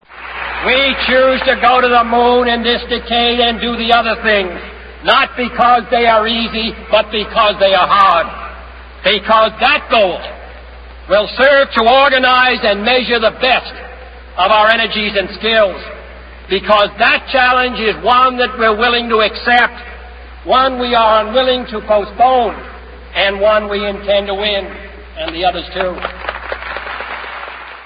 Dal celebre discorso di John Kennedy